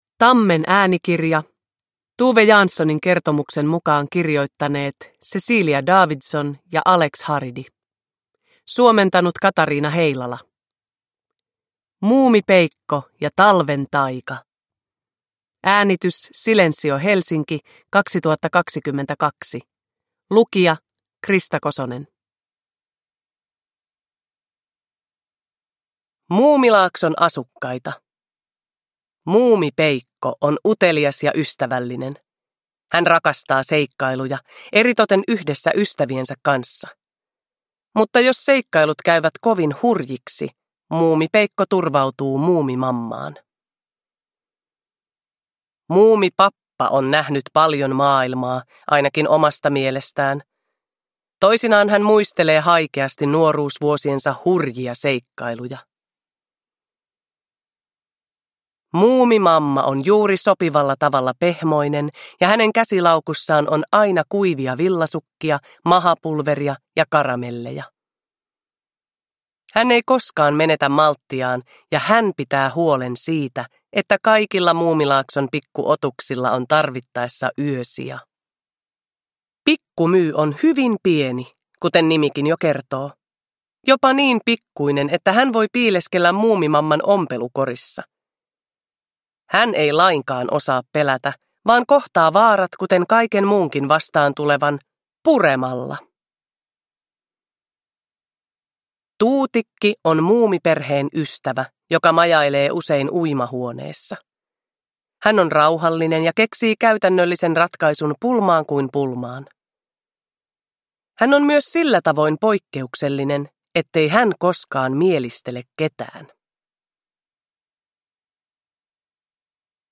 Muumipeikko ja talven taika – Ljudbok – Laddas ner
Uppläsare: Krista Kosonen